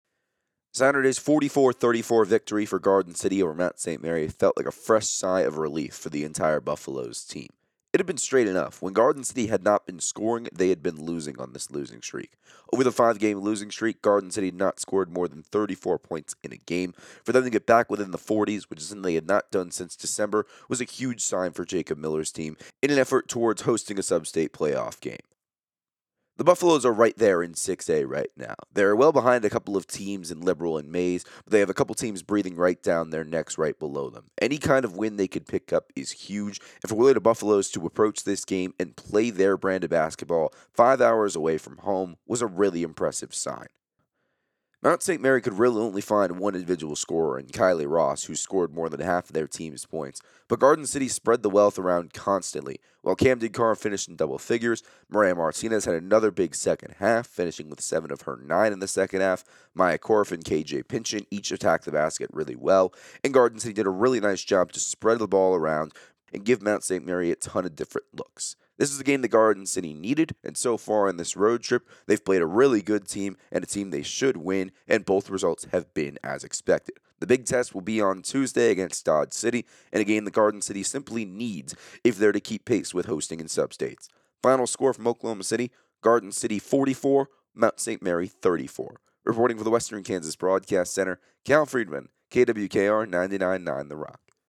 Audio Recap